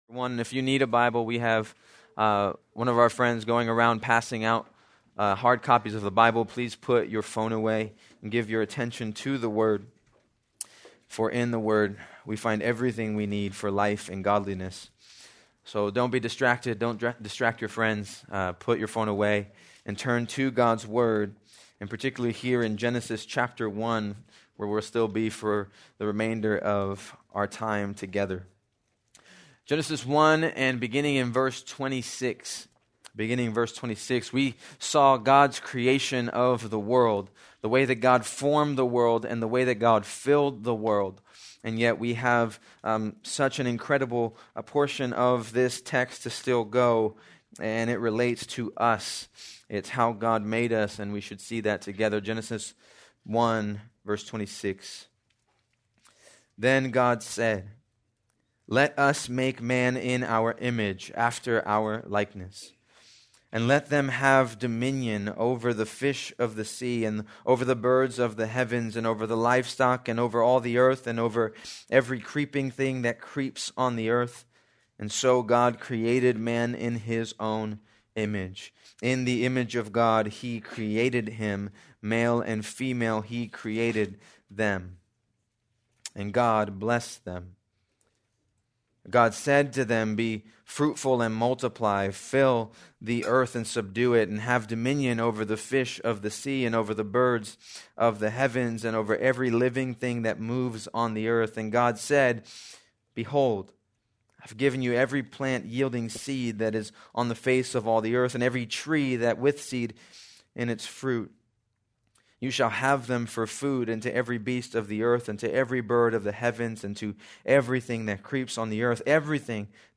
February 1, 2026 - Sermon